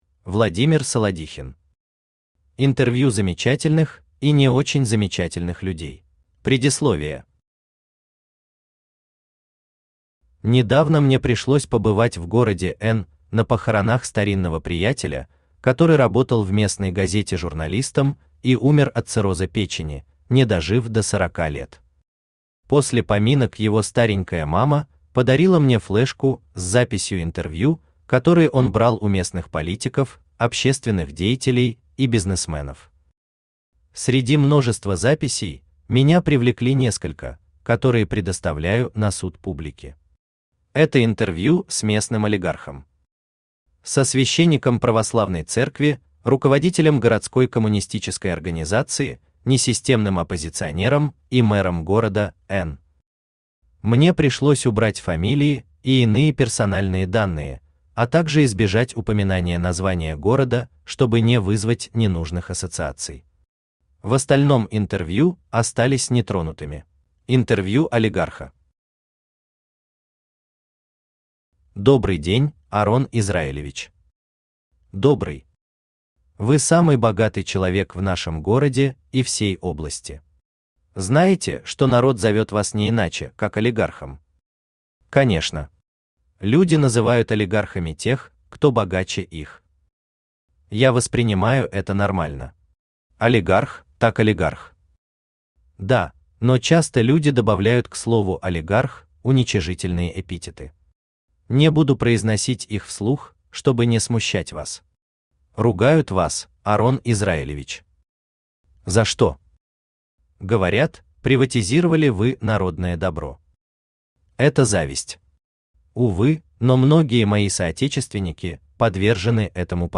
Аудиокнига Интервью замечательных и не очень замечательных людей | Библиотека аудиокниг
Aудиокнига Интервью замечательных и не очень замечательных людей Автор Владимир Евгеньевич Солодихин Читает аудиокнигу Авточтец ЛитРес.